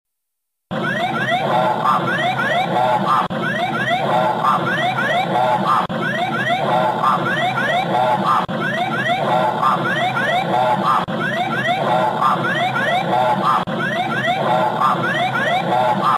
Gpws Whoop Pull Up DC 10 Sound Effects Free Download